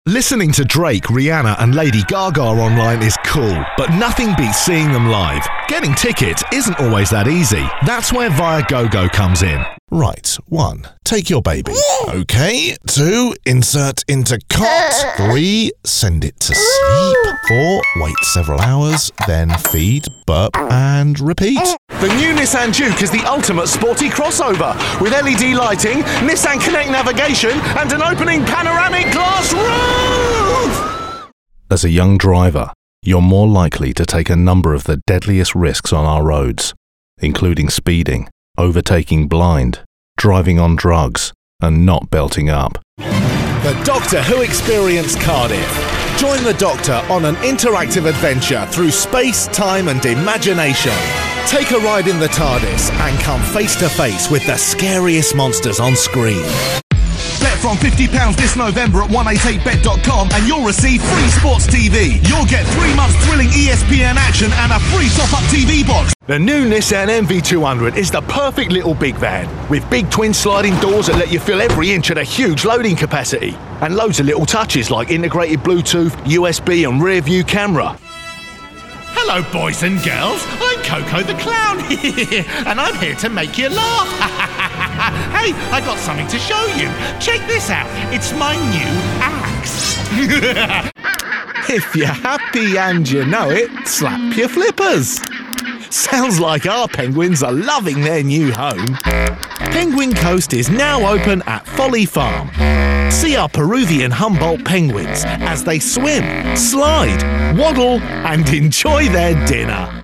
Current, relatable and versatile London voice. Characters, accents and impressions a specialty.
britisch
Sprechprobe: Werbung (Muttersprache):